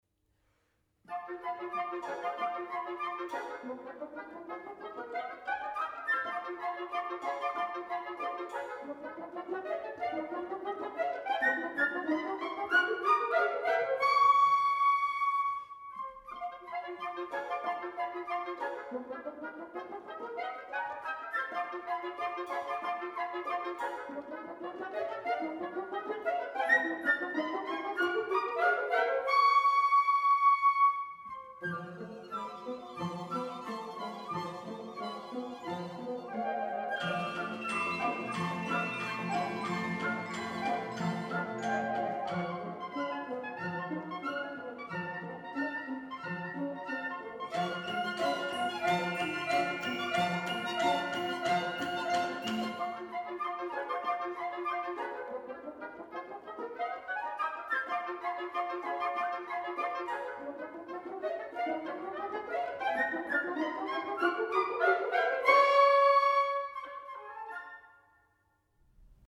Hör dir die Musik „Bilder einer Ausstellung“ von Modest Mussorgsky, gespielt von der Philharmonie Salzburg an.